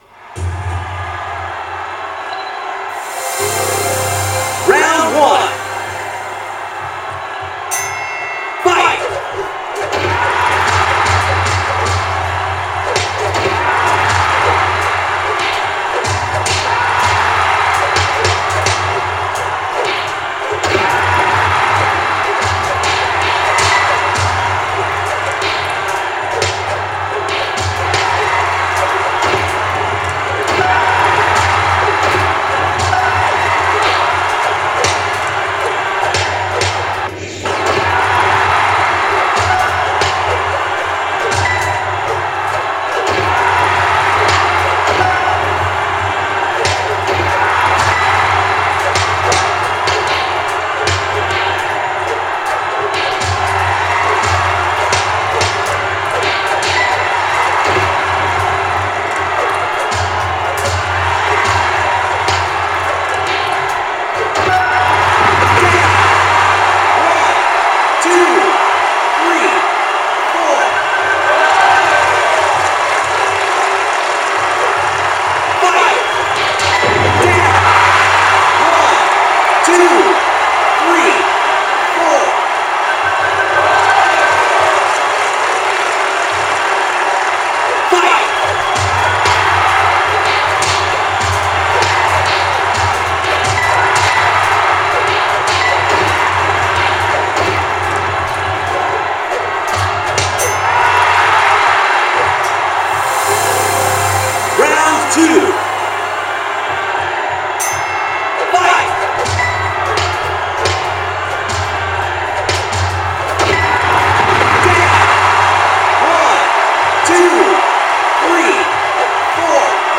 Quando diamo inizio all’incontro, dopo il gong e il segnale e “Fight!” dato dall’arbitro, dobbiamo cominciare a prendere a pugni l’avversario che ci sta davanti, semplicemente mimando il gesto con i due controller in mano.
Ascoltate bene i suoni provenienti sia dalla TV che dal telecomando: si possono carpire i pugni assestati, ricevuti e quelli andati a vuoto.